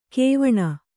♪ kēvaṇa